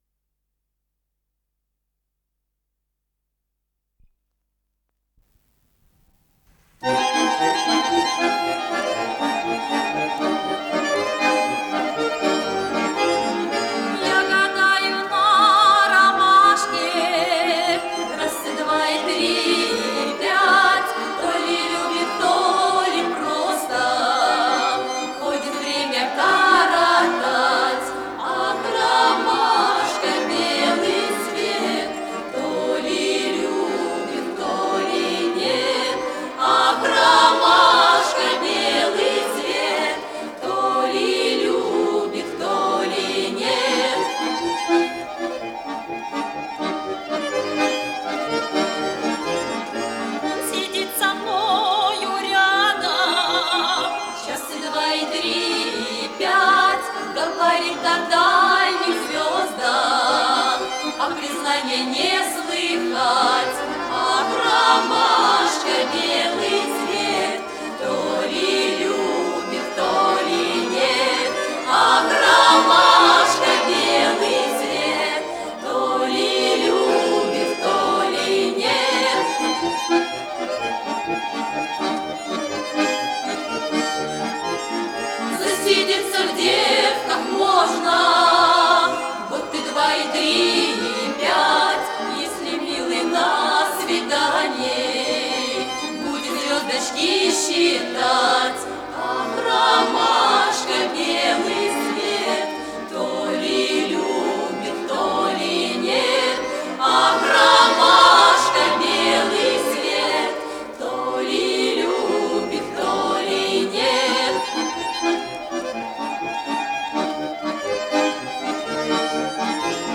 с профессиональной магнитной ленты
АккомпаниментДуэт баянистов
ВариантДубль моно